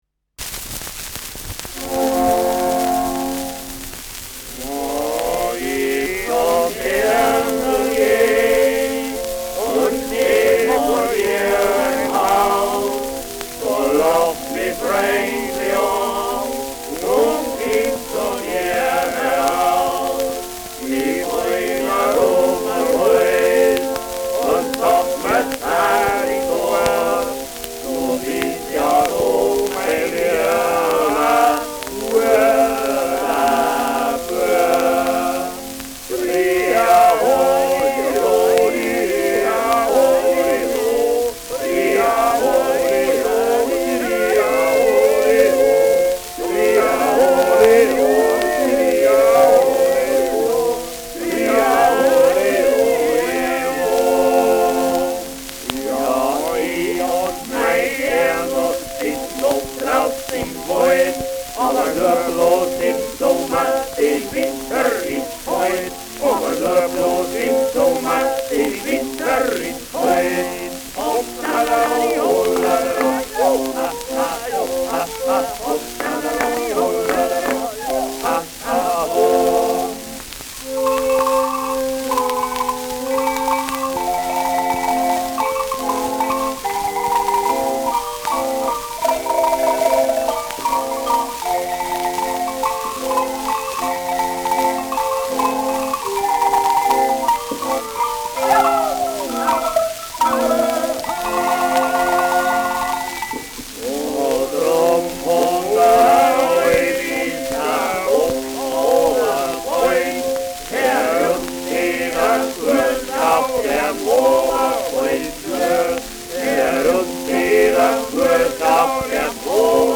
Almer G’sangl : mit Kuhglocken-Begleitung [Almhirten-Liedchen : mit Kuhglocken-Begleitung]
Schellackplatte
präsentes Rauschen : präsentes Knistern : leiert : vereinzeltes Knacken
Dachauer Bauernkapelle (Interpretation)